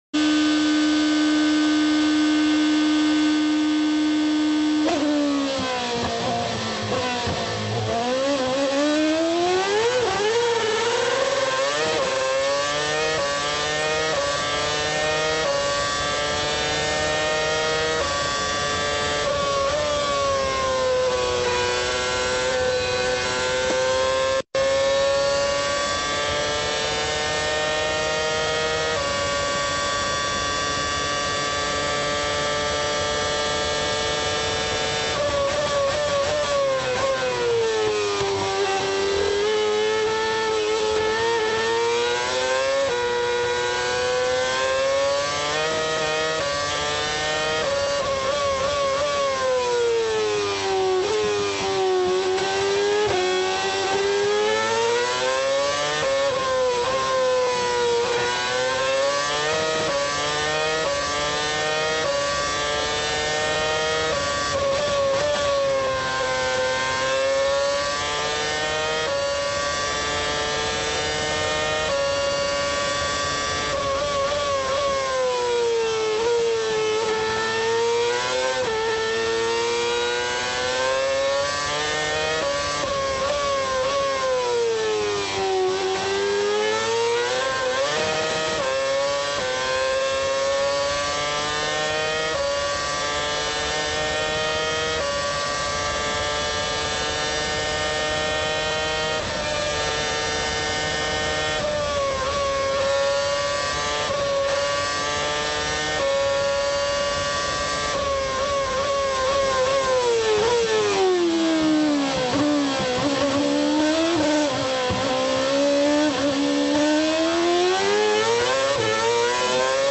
Onboard with Alonso last lap sound effects free download
Onboard with Alonso last lap at Spa 2008 after pit stop, climbing 8th to 4th